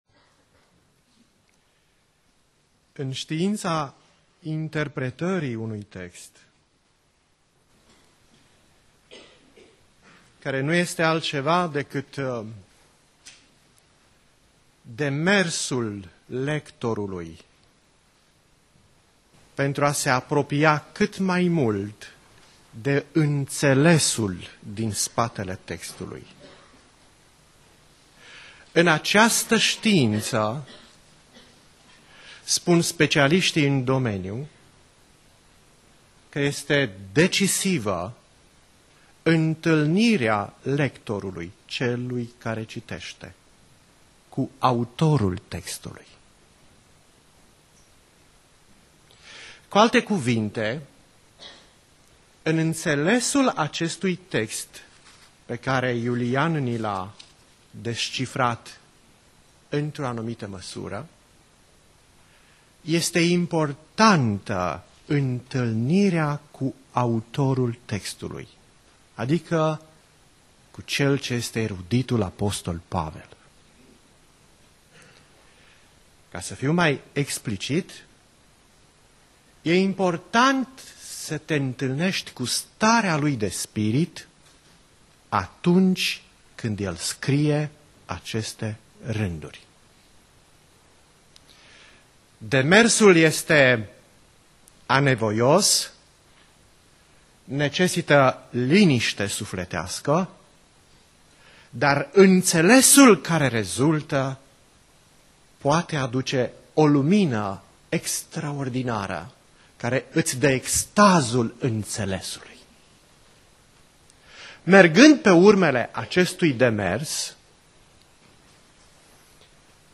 Predica Aplicatie- 2 Tesaloniceni Cap.2b